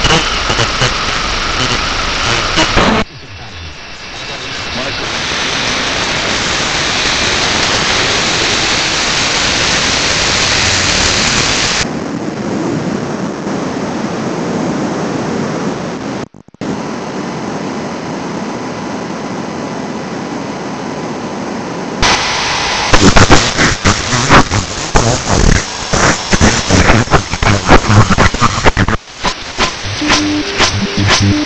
the Soma Messeiver topic got me thinking if there’s a software FM receiver, and after some looking around I found this very interesting website called WebSDR, basically it’s a web controller for bunch of receivers spread across the world listening to radio, the cool thing about this is you can listen to a broad band of signal rather focusing on a specific one, very similar to what the Soma device does, the web controller also allows instant sampling and saving the audio files, very cool